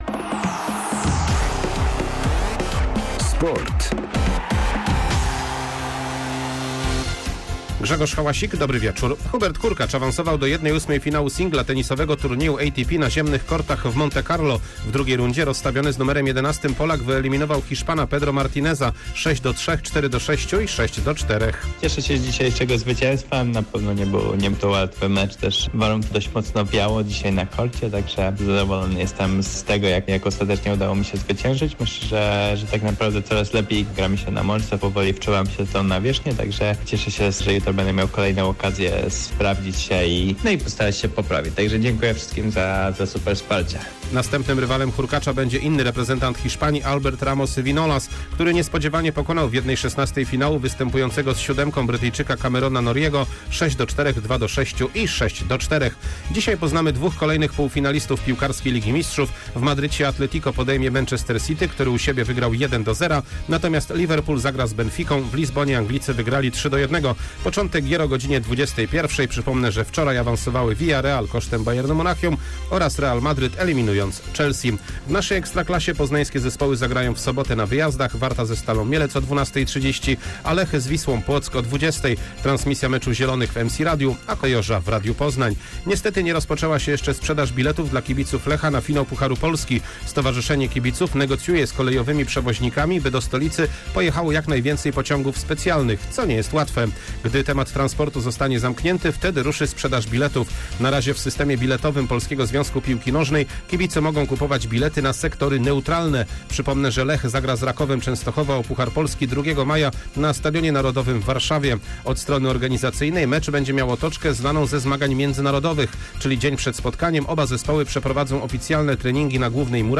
13.04.2022 SERWIS SPORTOWY GODZ. 19:05
W środowym serwisie sportowym Hubert Hurkacz opowiada o wygranym meczu w turnieju ATP w Monte Carlo. Ponadto wieści o Lechu Poznań w kontekście historycznym oraz wyjaśniamy, dlaczego kibice Kolejorza nie mogą jeszcze kupić biletów na swoje sektory na finał Pucharu Polski.